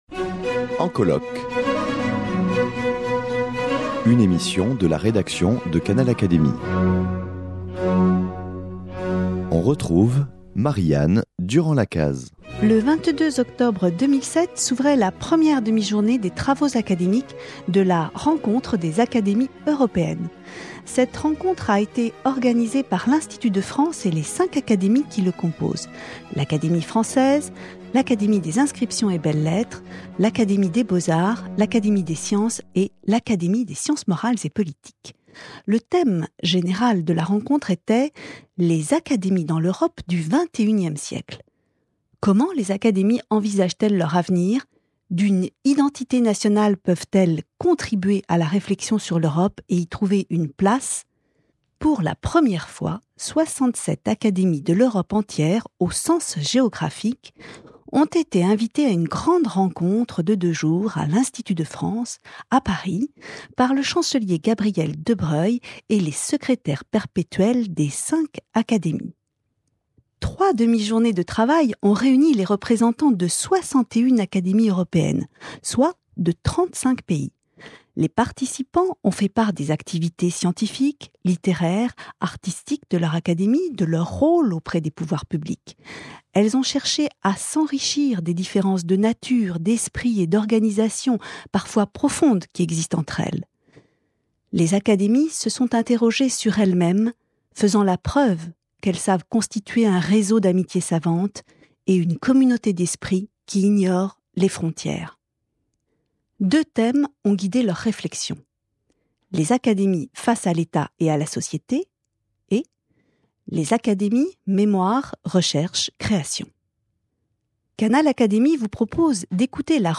Le chancelier de l’Institut de France Gabriel de Broglie, Jean-Claude Casanova et Michel Zink, membres de l’Institut répondent avec leurs confrères européens à cette question fondamentale. Le débat et les discussions qui ont suivi l’exposé de synthèse illustrent la richesse des échanges.